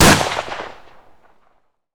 Rifle Gun Shot Sound
weapon
Rifle Gun Shot